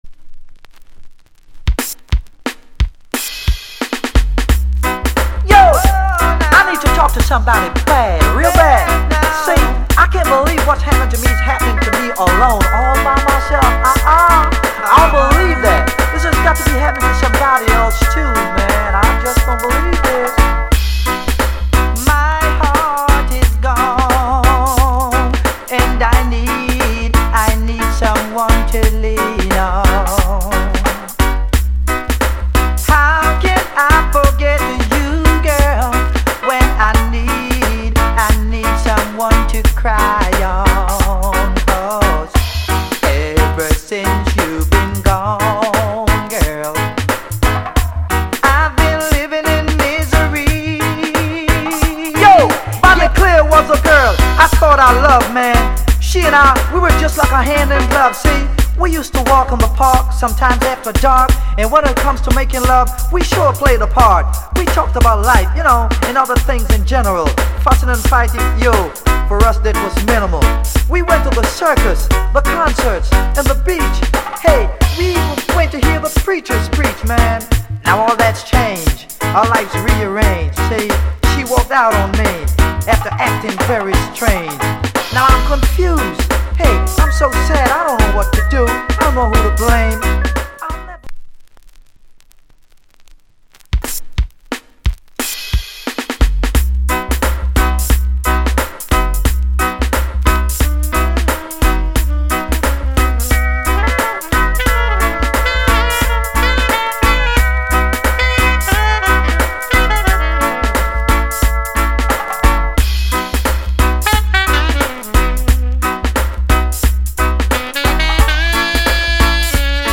Genre ReggaeAfter90s / [A] Male Vocal [B] Inst
シャインヘッドを意識してか，途中でRap調になるところが面白い。
Saxインスト